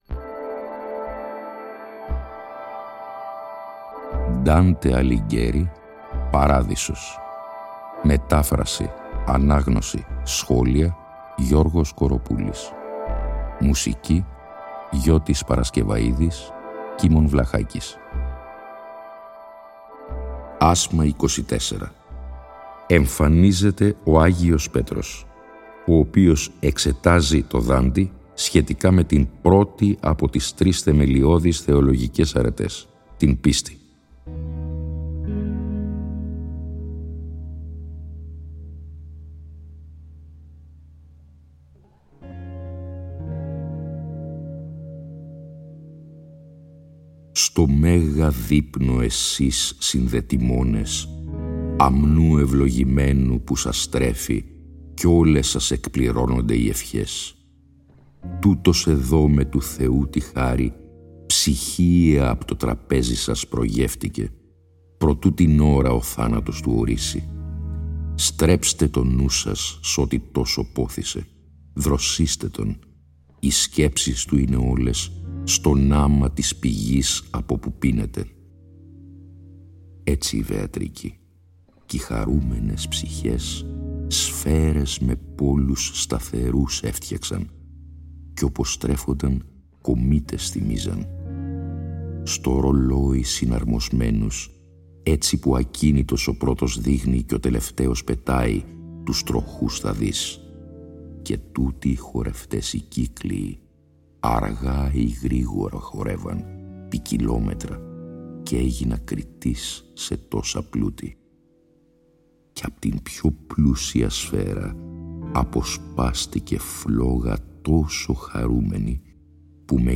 Η ανάγνωση των 33 ασμάτων του «Παραδείσου», σε 21 ημίωρα επεισόδια, (συνέχεια της ανάγνωσης του «Καθαρτηρίου», που είχε προηγηθεί) συνυφαίνεται και πάλι με μουσική